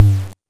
ChipTune Tom 02.wav